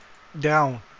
speech-commands_down.wav